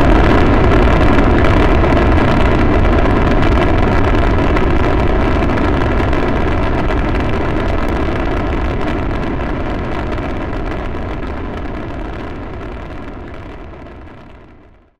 soyuzTakeoff.ogg